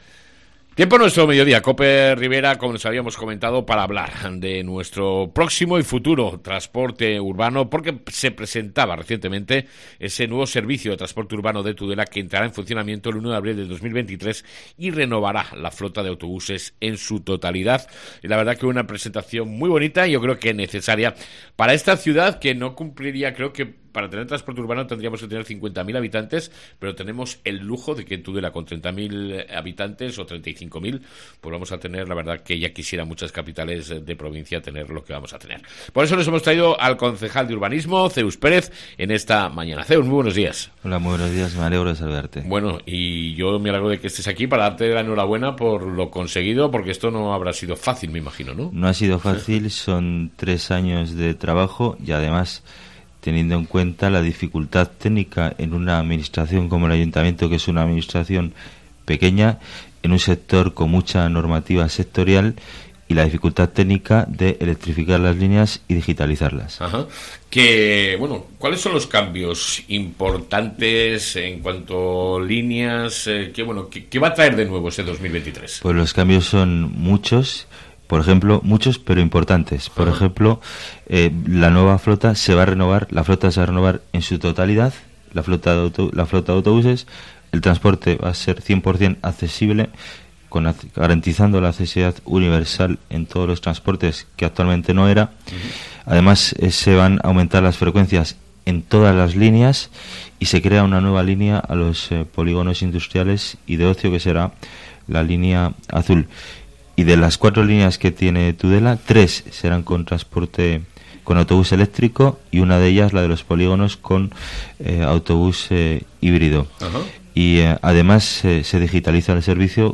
Entrevista con Zeus Pérez, concejal de urbanismo